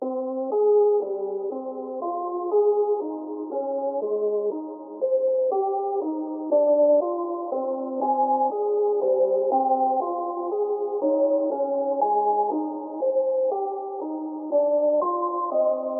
标签： 120 bpm Trap Loops Rhodes Piano Loops 2.69 MB wav Key : C Ableton Live
声道立体声